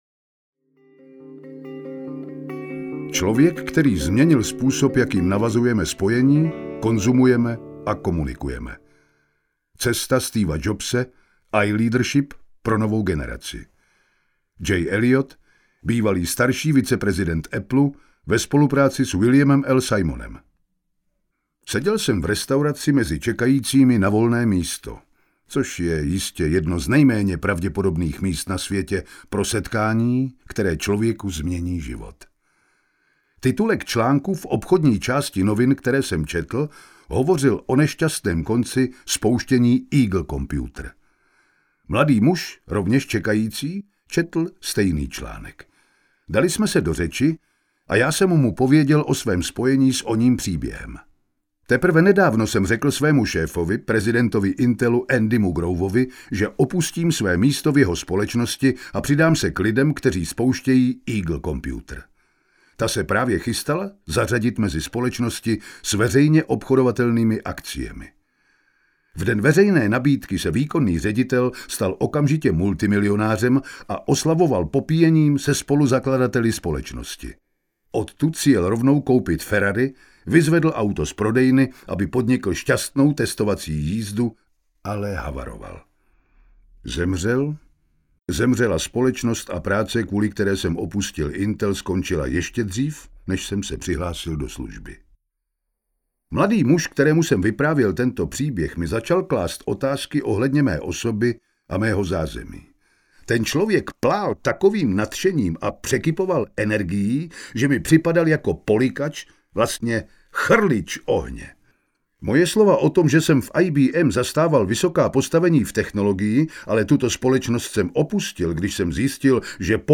Cesta Steva Jobse audiokniha
Ukázka z knihy